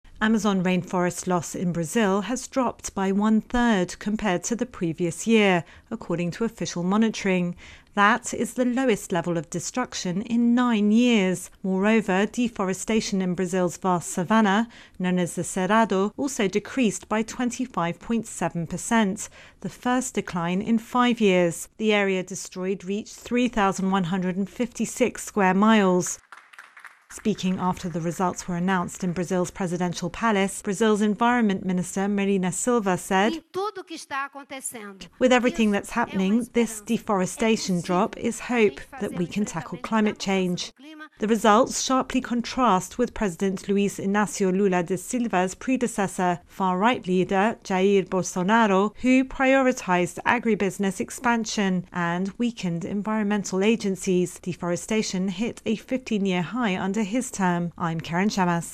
AP correspondent